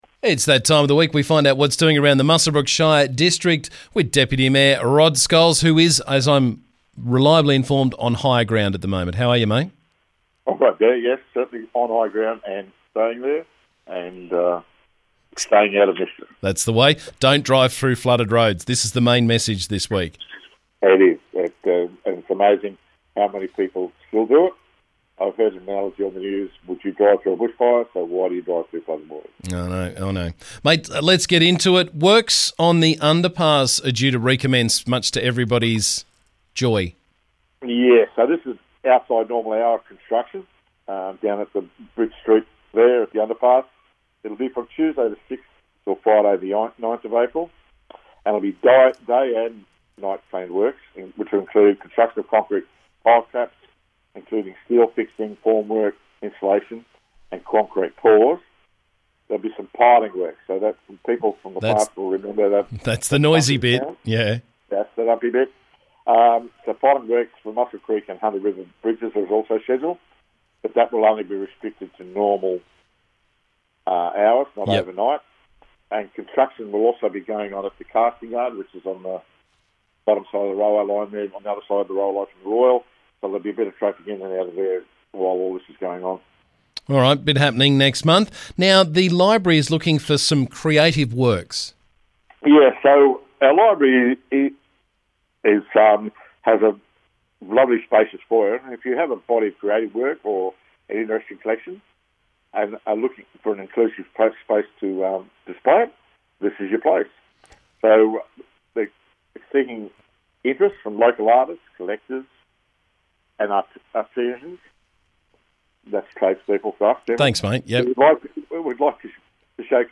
Muswellbrook Shire Council Deputy Mayor Rod Scholes joined me to talk about the latest from around the district.